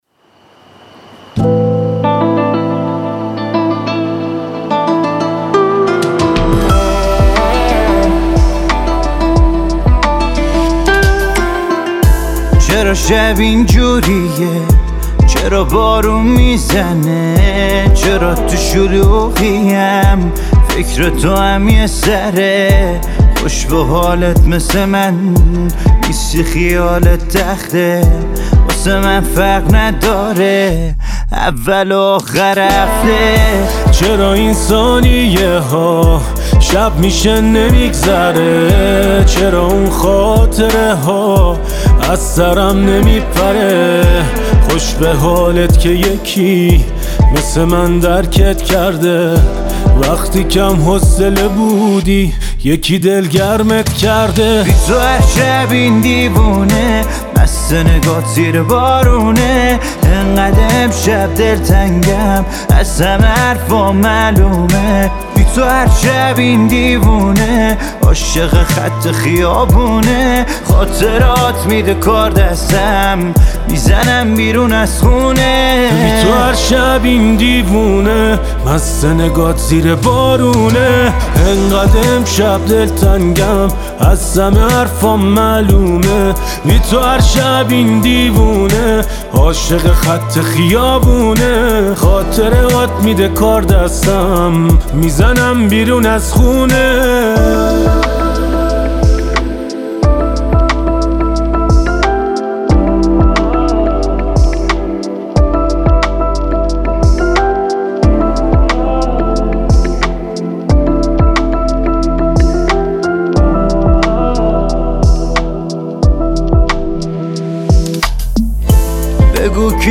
• موسیقی پاپ ایرانی